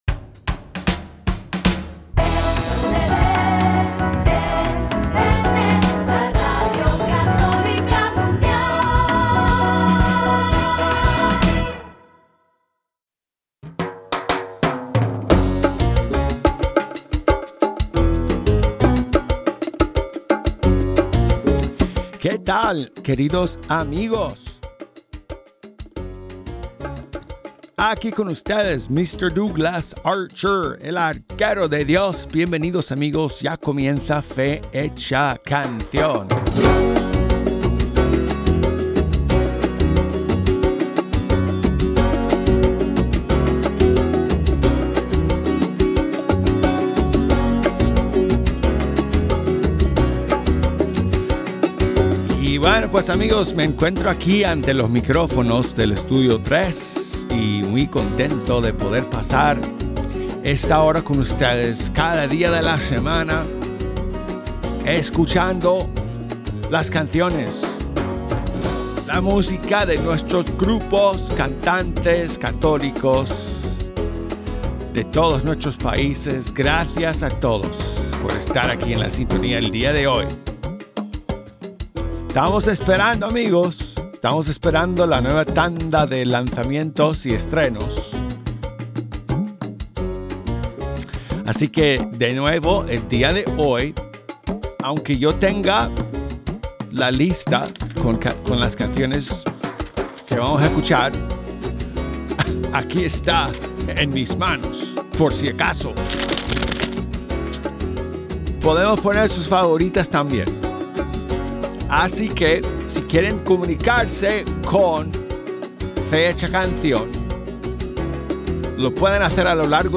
Fe hecha canción es el programa de EWTN Radio Católica Mundial que promociona la música de los grupos y cantantes católicos del mundo hispano.